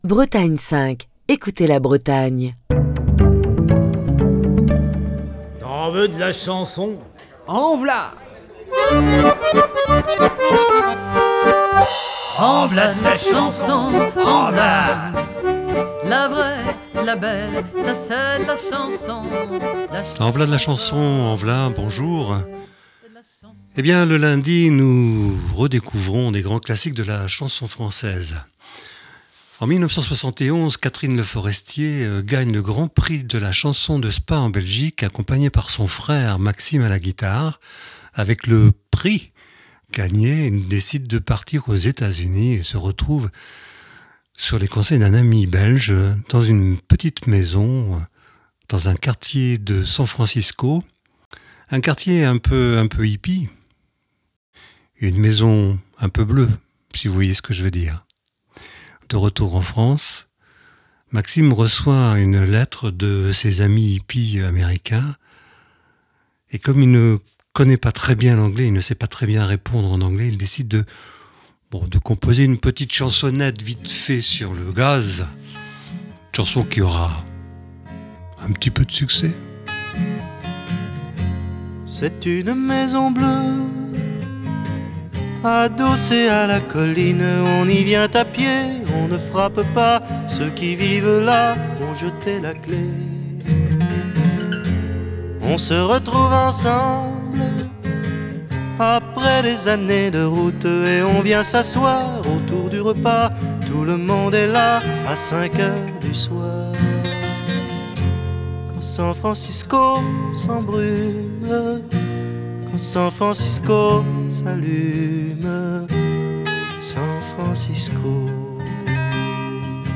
Chronique du 6 février 2023.